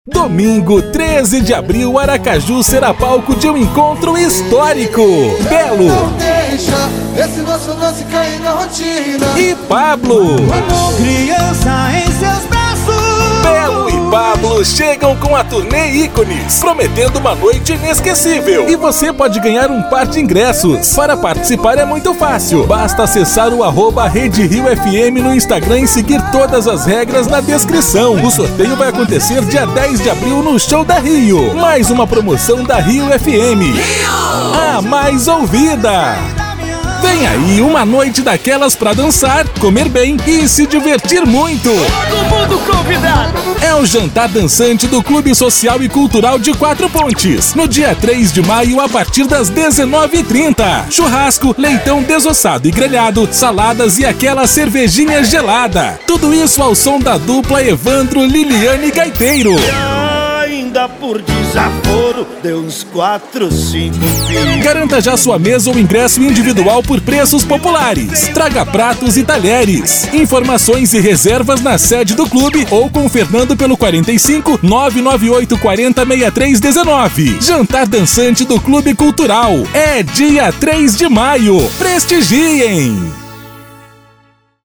Jovem Animado: